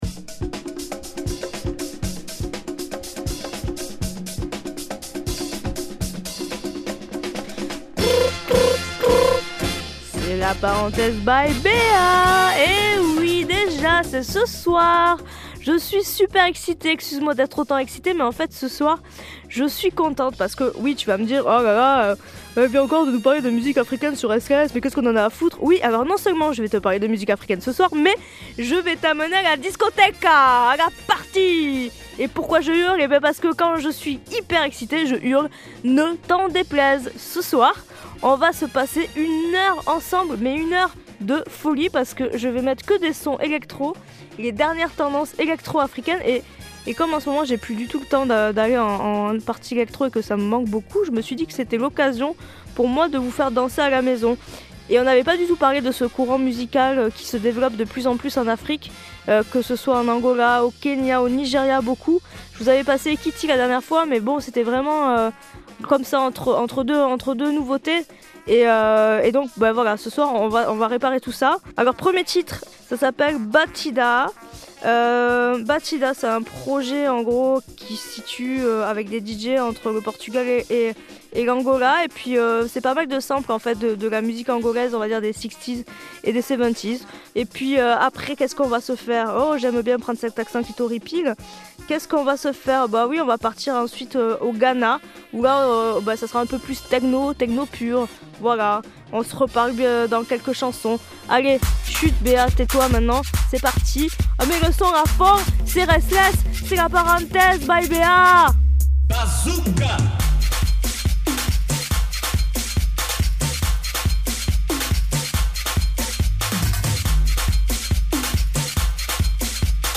Spéciale électro « Made in Afrique » !